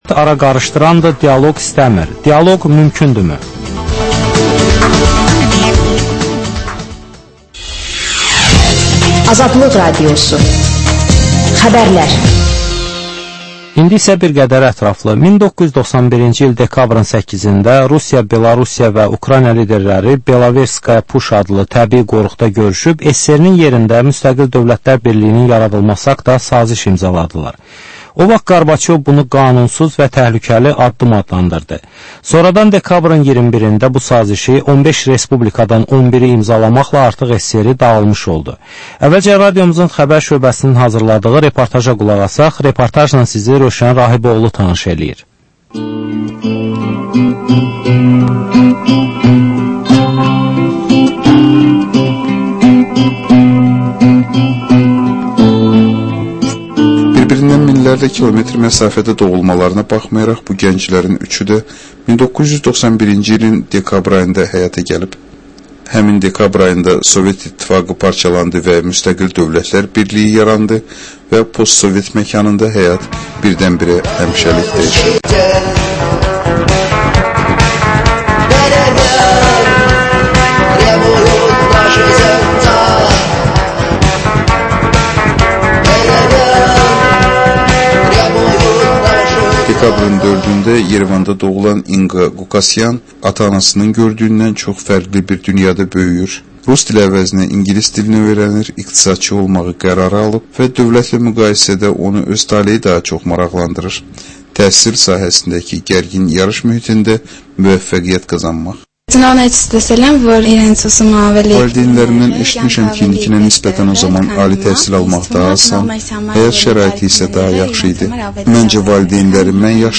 Reportaj, müsahibə, təhlil